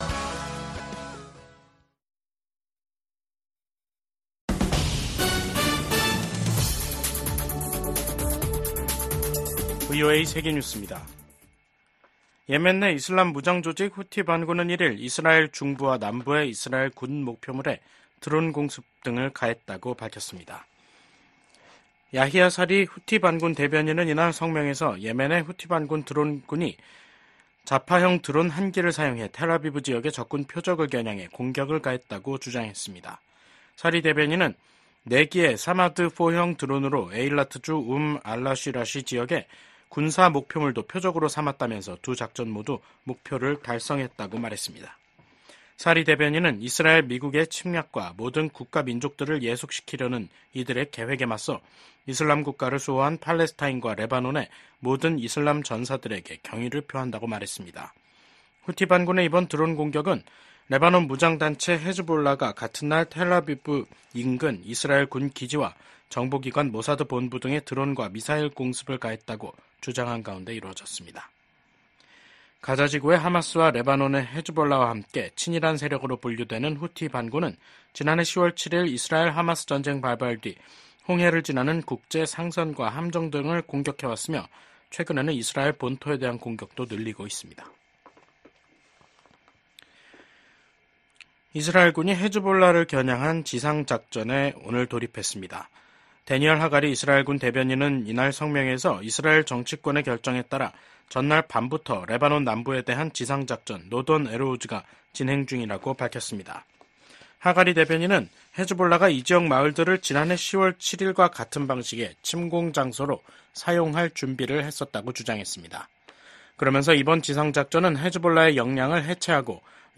VOA 한국어 간판 뉴스 프로그램 '뉴스 투데이', 2024년 10월 1일 3부 방송입니다. 윤석열 한국 대통령은 북한이 핵무기를 사용하려 한다면 정권 종말을 맞게 될 것이라고 경고했습니다. 김성 유엔주재 북한 대사가 북한의 핵무기는 자위권을 위한 수단이며 미국과 핵 문제를 놓고 협상하지 않겠다고 밝혔습니다.